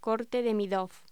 Locución: Corte demidoff
voz